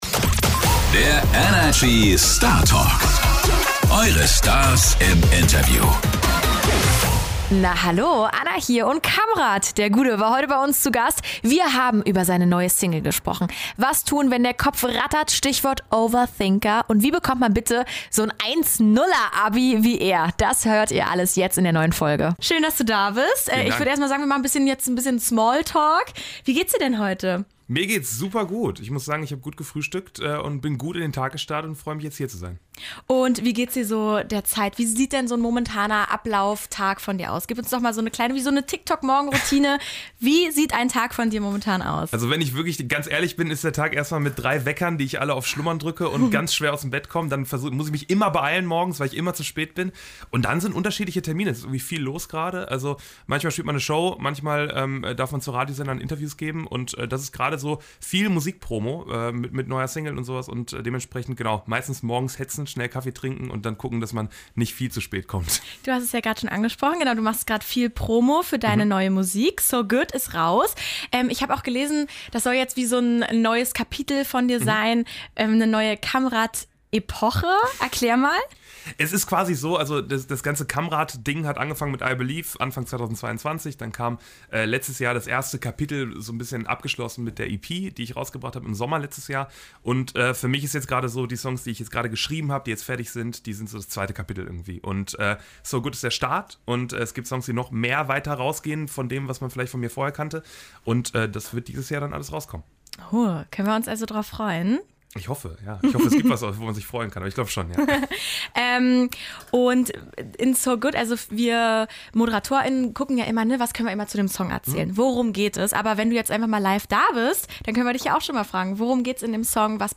Beschreibung vor 2 Jahren In dieser Startalk Folge ist ein Mann zu Gast, von dem die meisten nur den Nachnamen kennen.